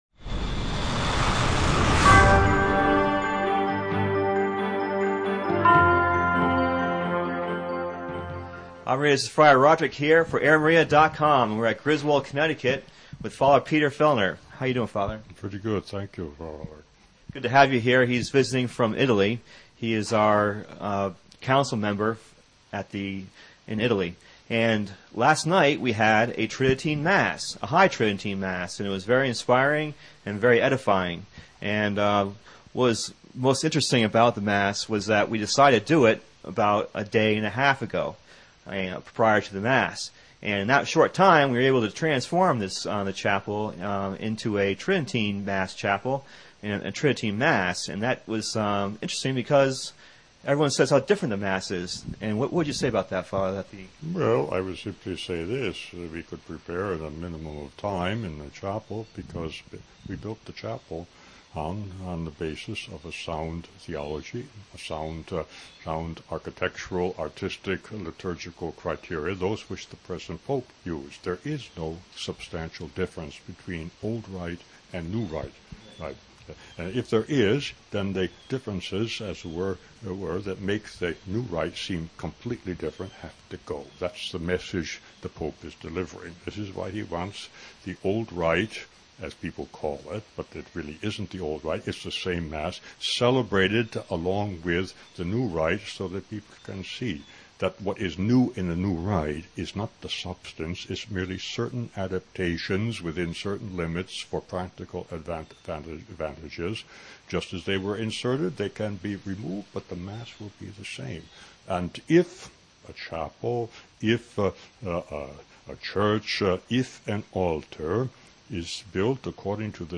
gave an interview